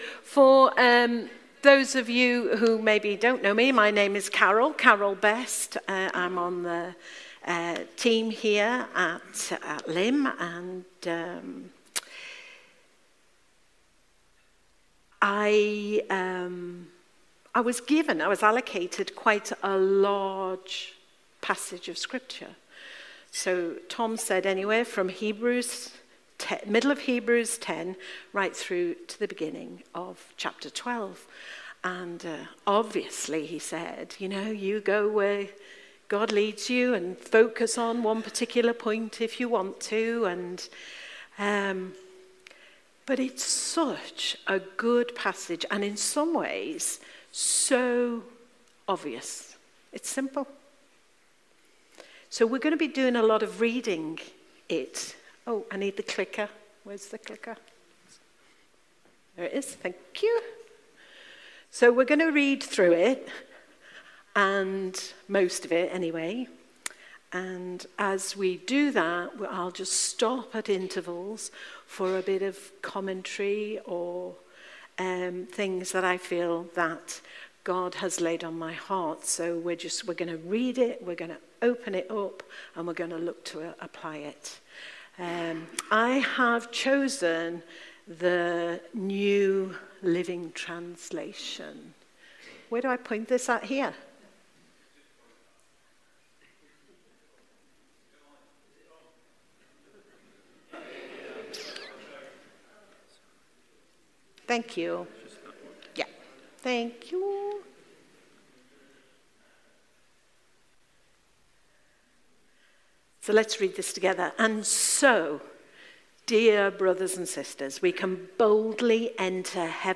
Passage: Hebrews 10:19-12:3 Service Type: Sunday Morning